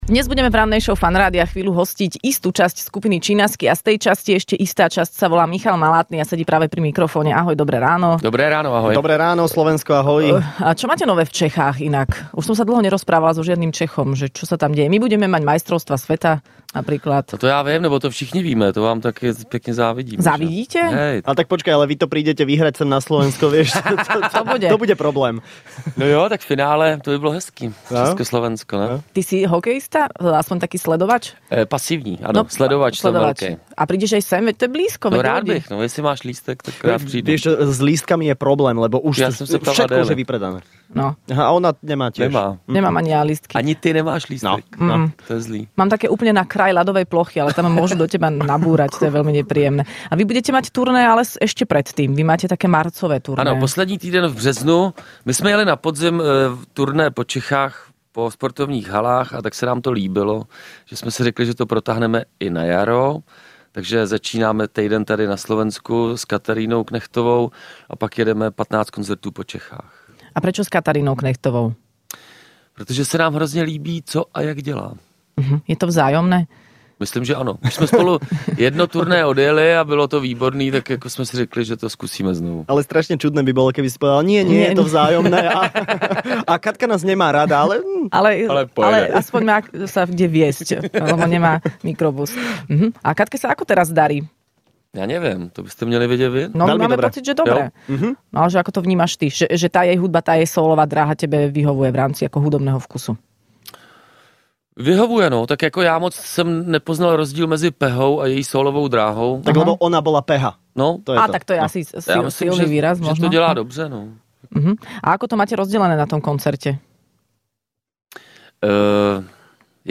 Skupina Chinaski prišla do Rannej šou predstaviť svoj nový album